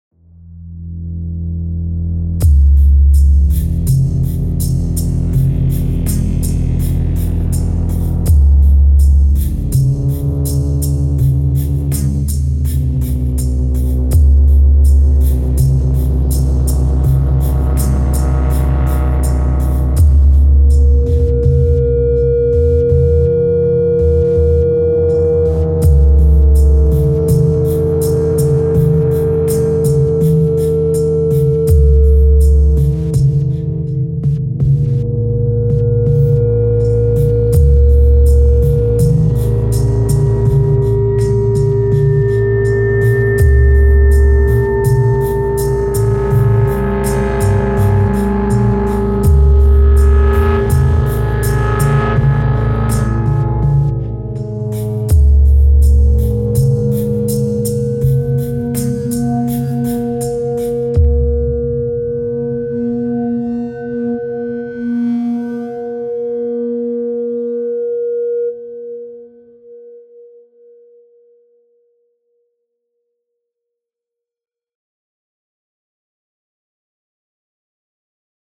Dystopian